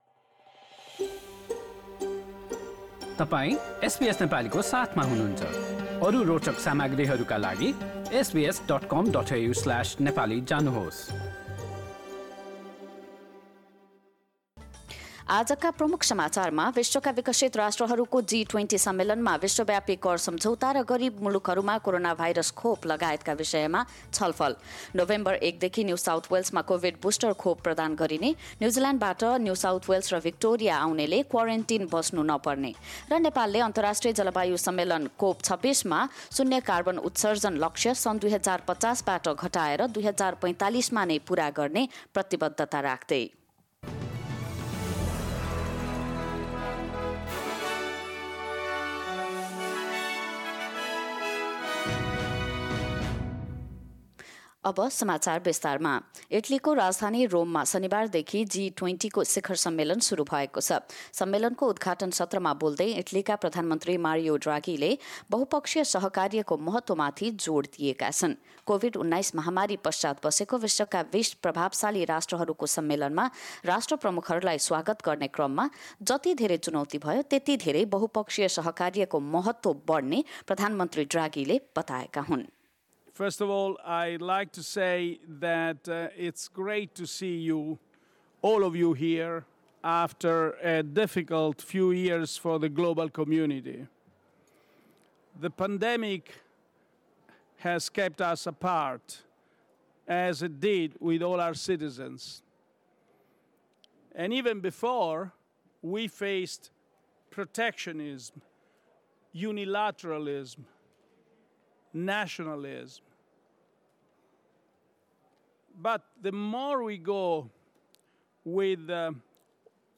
एसबीएस नेपाली अस्ट्रेलिया समाचार: आइतबार ३१ अक्टोबर २०२१